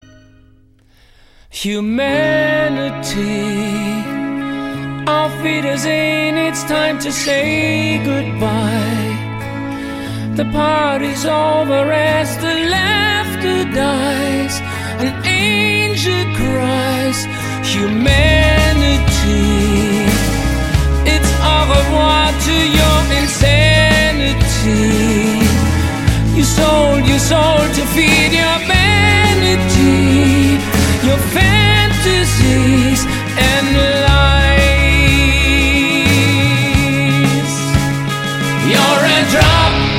рок , баллады